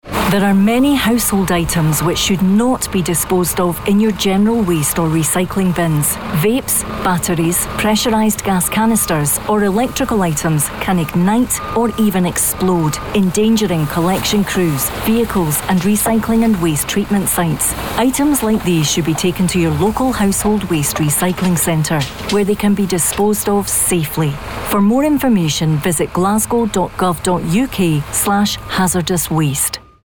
hazardous-waste-Radio-Audio.mp3